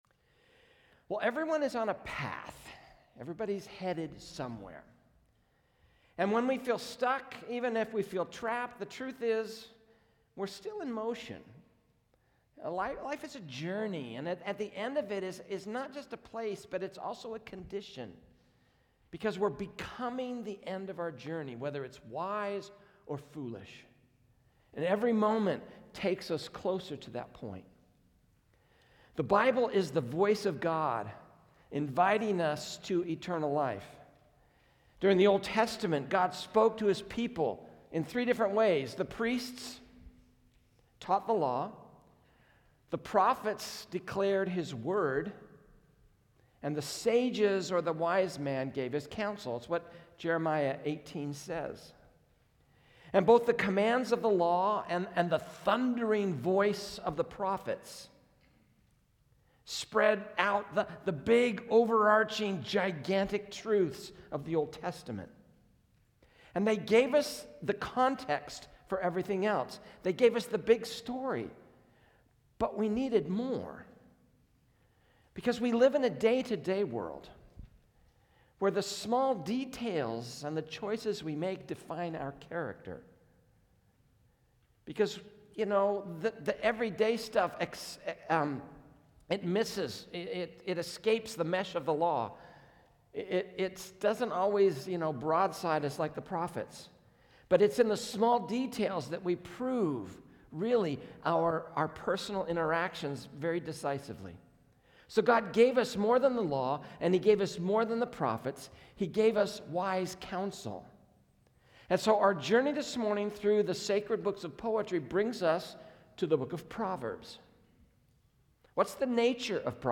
A message from the series "Timeless."